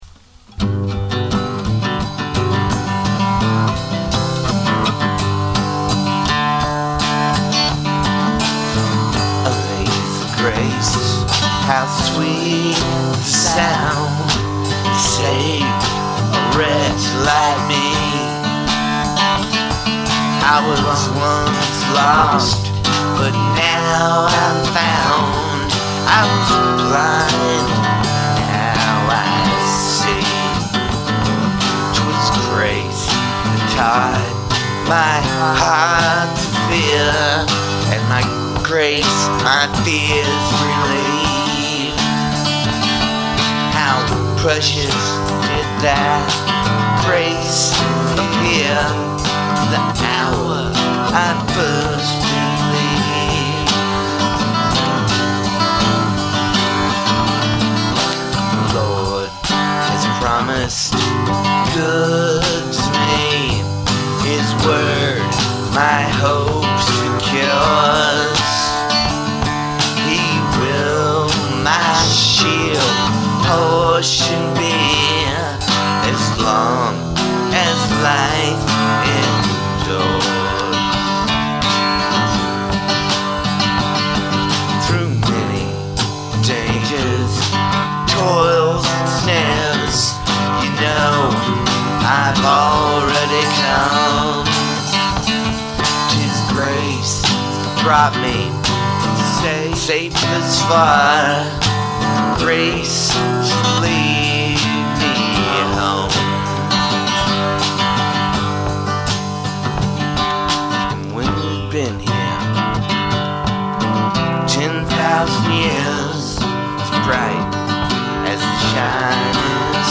an Old American Spiritual Song